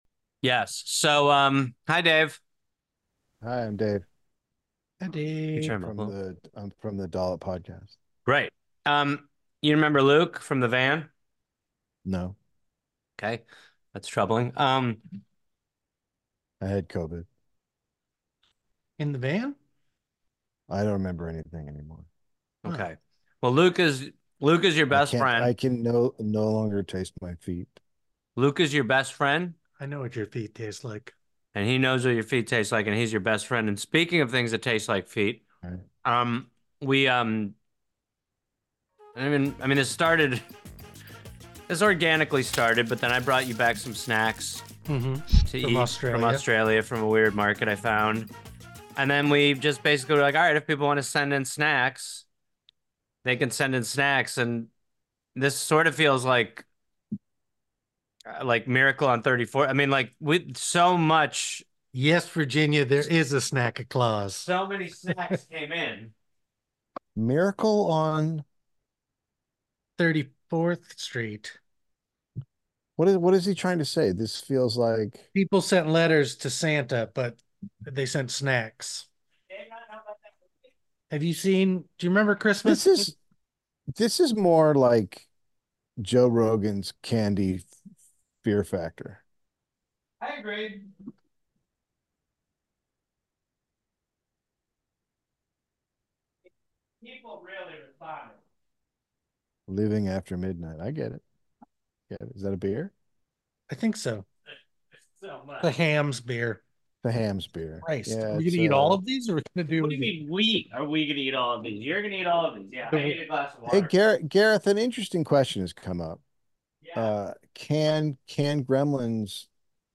Gross noises